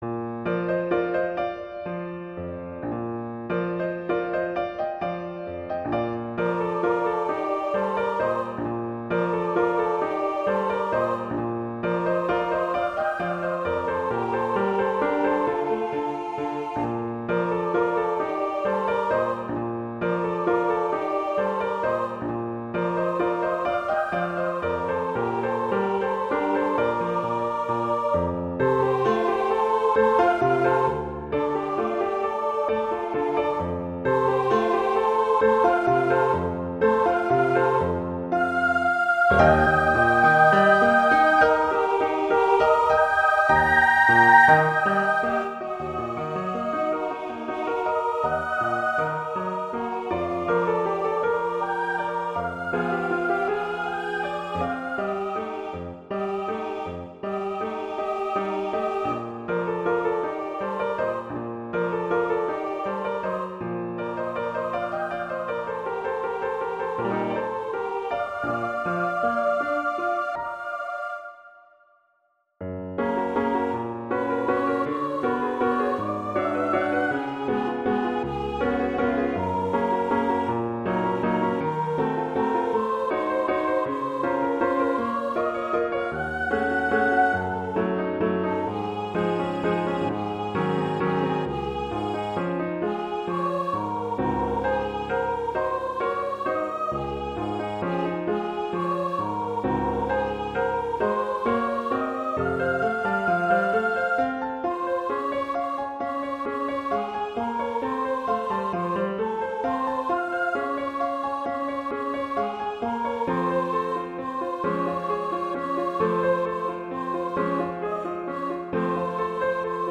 arrangement for two voices and piano
classical, wedding, festival, love
Bb major
♩. = 44 BPM